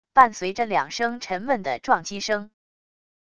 伴随着两声沉闷的撞击声wav音频